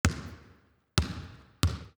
Basketball Dribbling.m4a